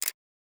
soft-button-click.wav